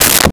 Briefcase Latch
Briefcase Latch.wav